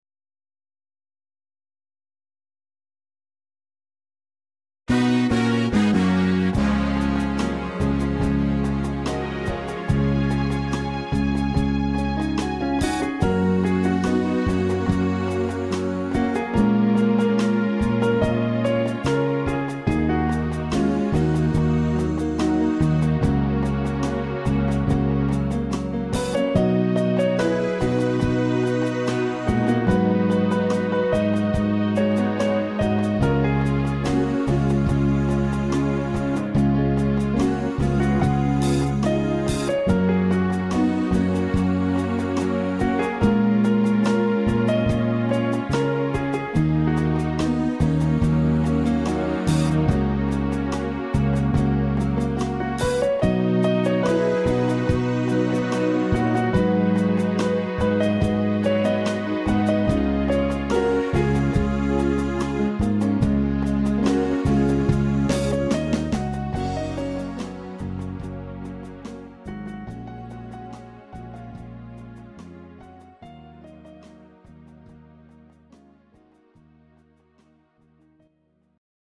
Ballads Music